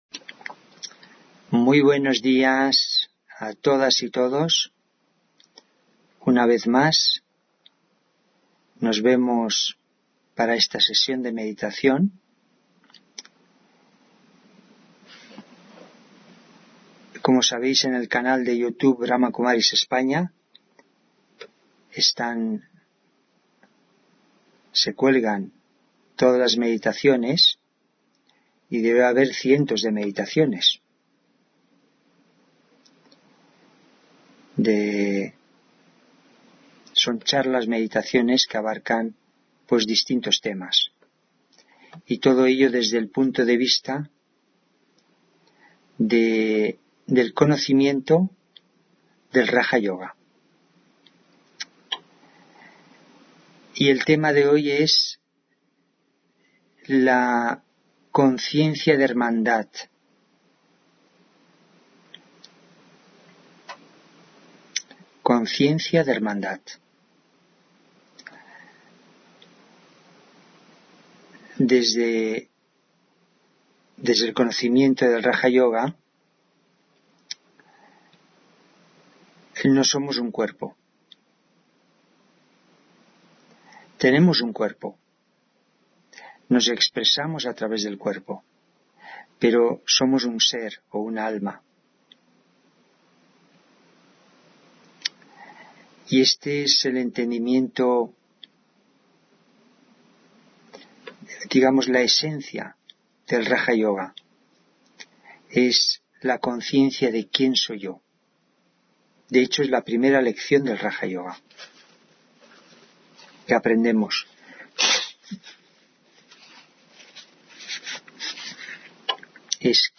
Meditación de la mañana: Un intelecto limpio, claro y sutil